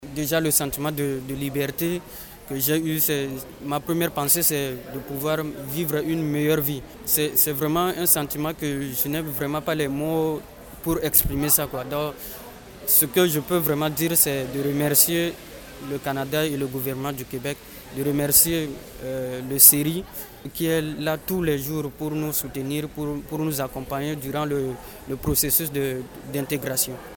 En cette journée mondiale des réfugiés, Solidarité Ethnique Régionale de la Yamaska (SERY) a tenu un point de presse ce vendredi à Granby afin de souligner le tout.